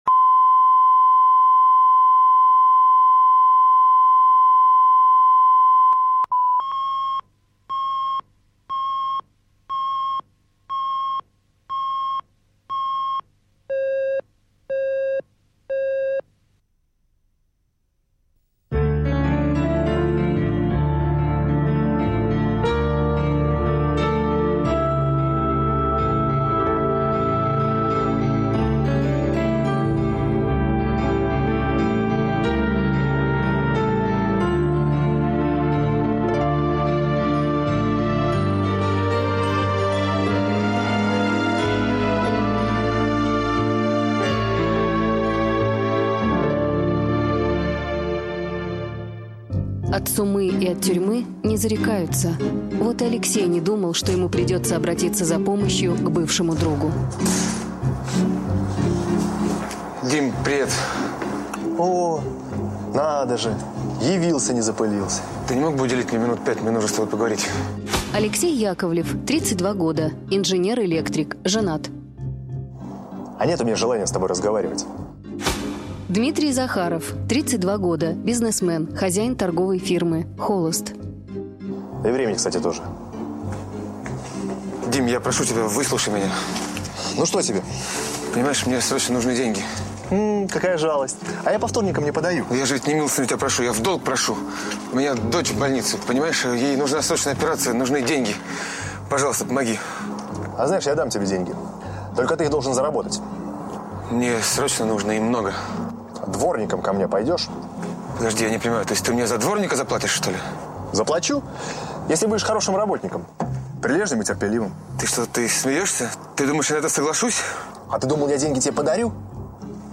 Аудиокнига Любой ценой | Библиотека аудиокниг
Прослушать и бесплатно скачать фрагмент аудиокниги